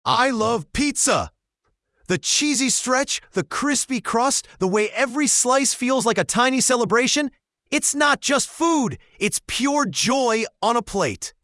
Here's a voice from OpenAI, reading the same script with expressive emotions.
AI voices can accurately capture the range of human emotion.
AI voice that loves pizza